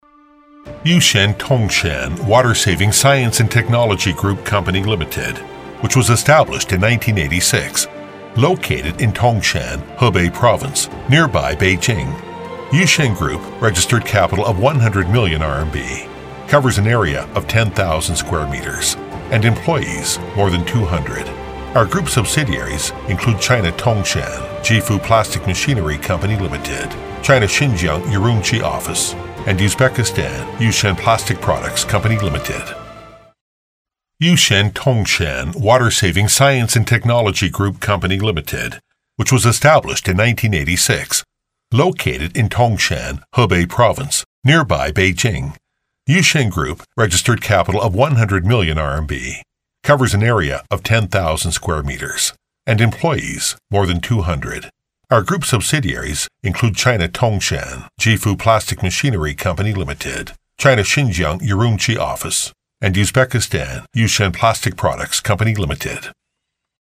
男英3号（外籍）